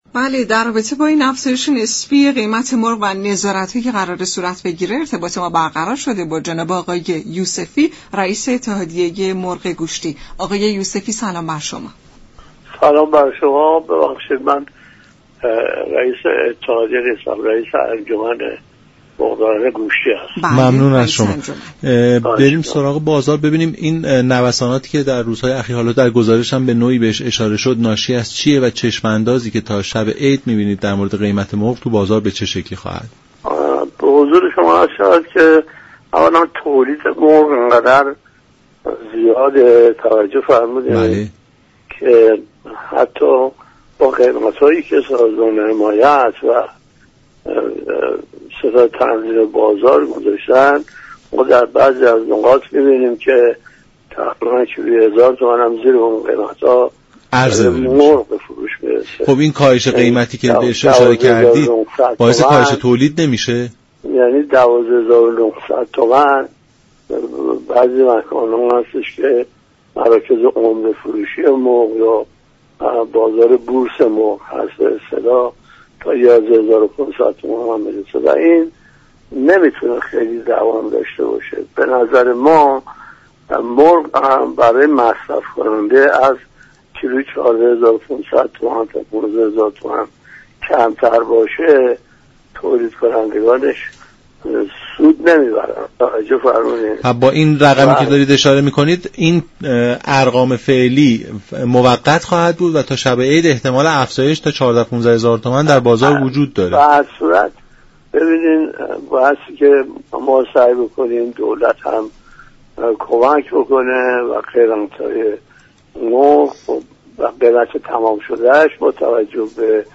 در گفت و گو با برنامه «نمودار»